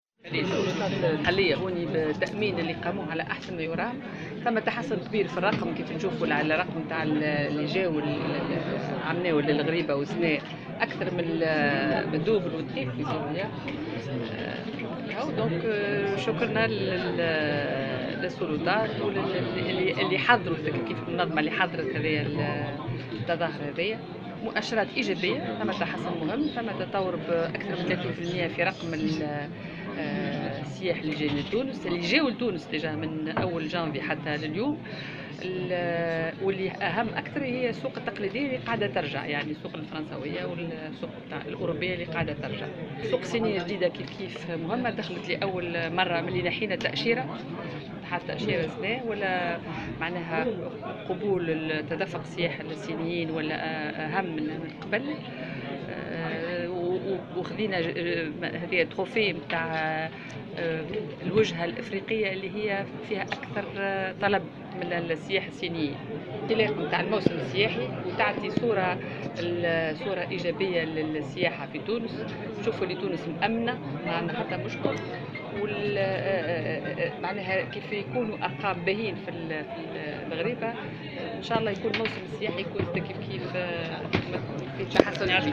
أكدت وزيرة السياحة سلمى اللومي في تصريح لمراسلة الجوهرة "اف ام" اليوم الأحد على هامش اختتام زيارة الغريبة لموسم 2017 أن السلطات المحلية بجزيرة جربة عملت على تأمين التظاهرة ونجحت في ذلك.